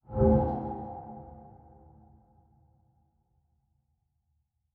Negative Effect 15.wav